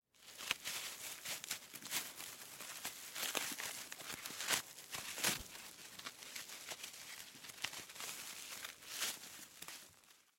Звуки веревки
Шуршание узла на канате или веревке (елозит по траве)